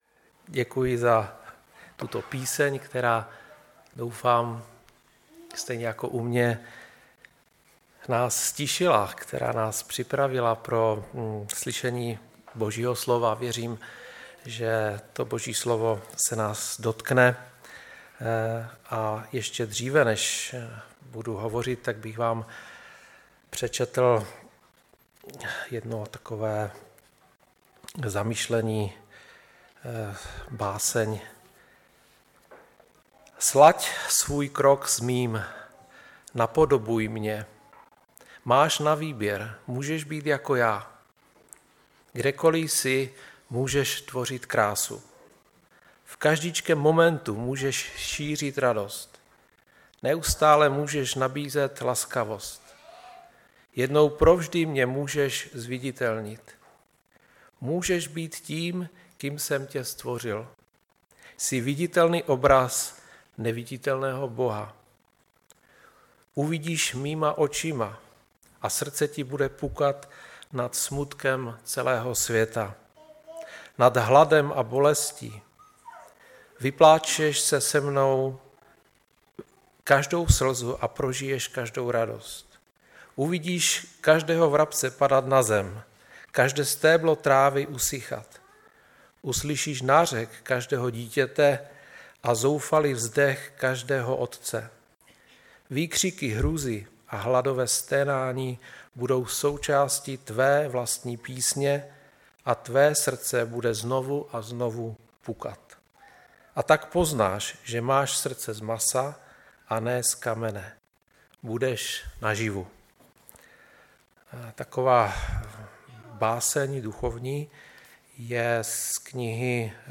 Kazatel